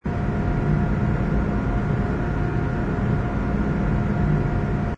ambience_bar_ground_larger.wav